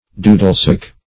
Search Result for " doodlesack" : The Collaborative International Dictionary of English v.0.48: doodlesack \doo"dle*sack`\, n. [Cf. G. dudelsack.] The Scotch bagpipe.